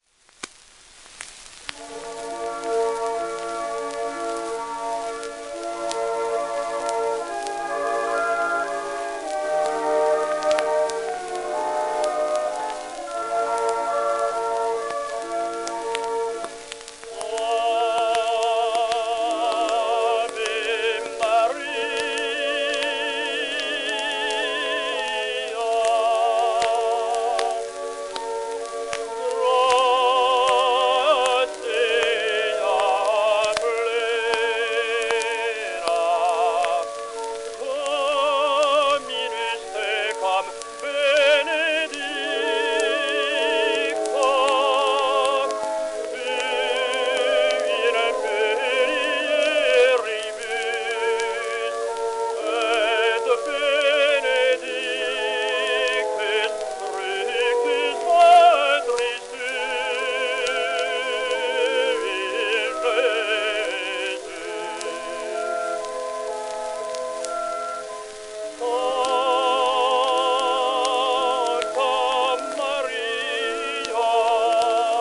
w/オーケストラ
1911年録音
旧 旧吹込みの略、電気録音以前の機械式録音盤（ラッパ吹込み）